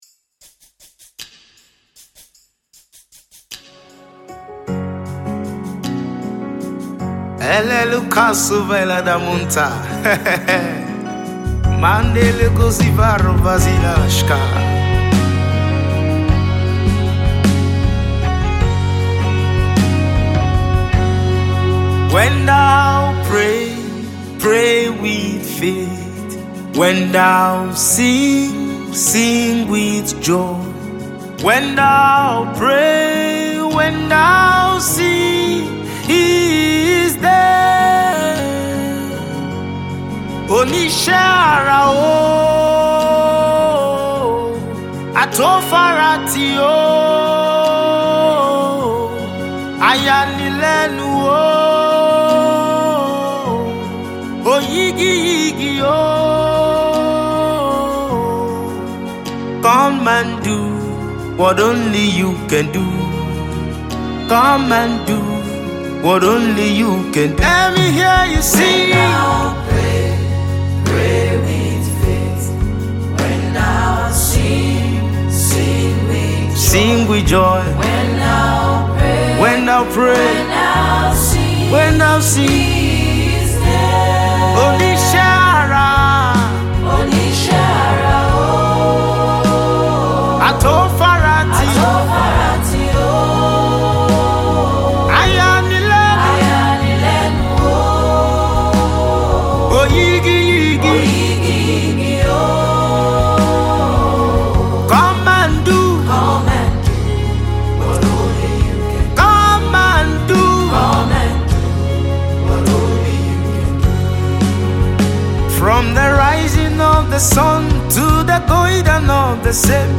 blend contemporary beats with rich African rhythms